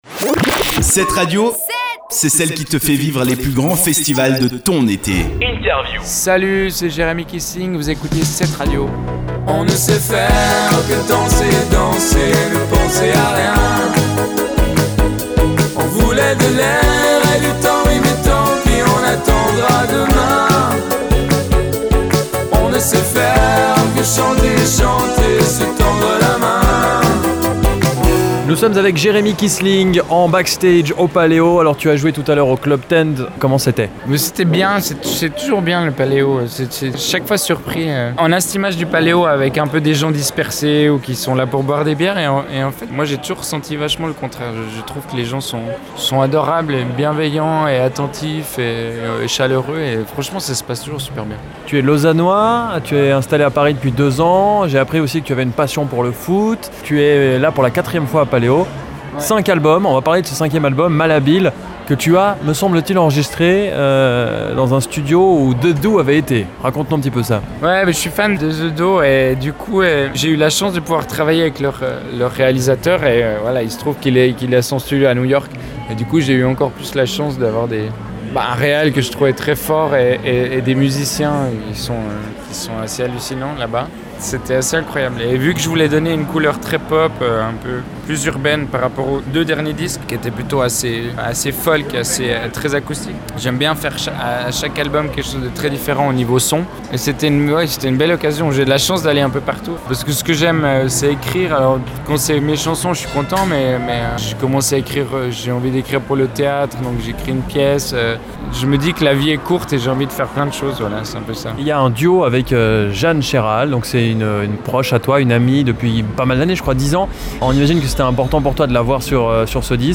Interview de Jérémie Kisling
INTERVIEW-JEREMIE-KISLING-PALEO.mp3